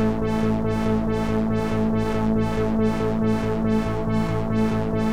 Index of /musicradar/dystopian-drone-samples/Tempo Loops/140bpm
DD_TempoDroneD_140-A.wav